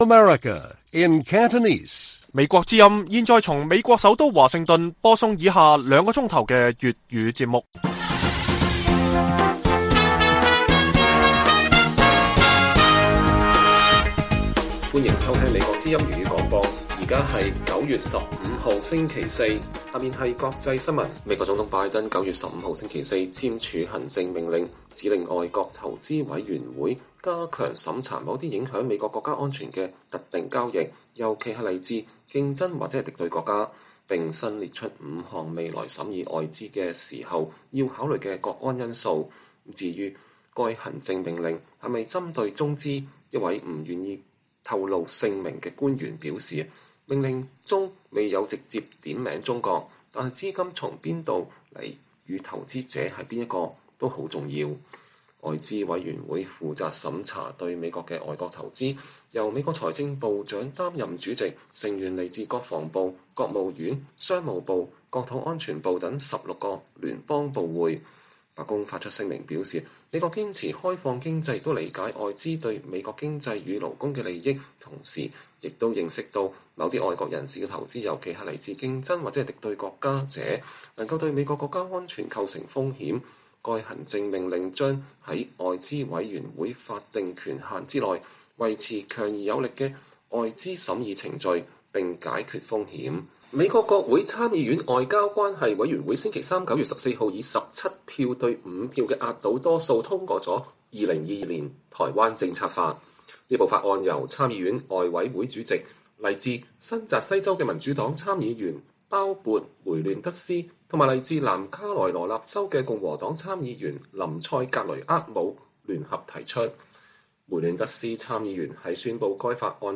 粵語新聞 晚上9-10點: 拜登簽行政令嚴審“敵對”外資 白宮：政令未說針對中國